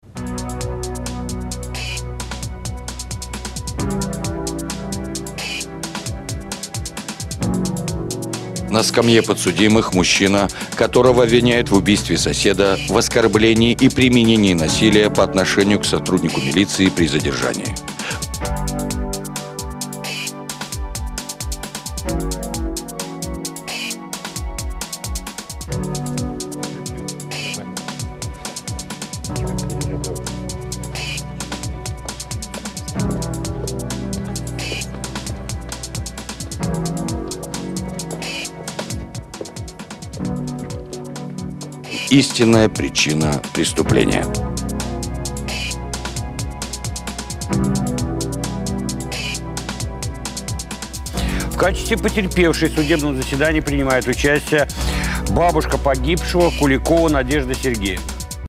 Трек, что во вложении, сделан на Korg Triton Extreme.
Собственно, основной замес в том, что клавишные звучат так, как и звучат, а вот орган на заднем плане звучит как-то по-другому, выше, как будто его потом наруливали.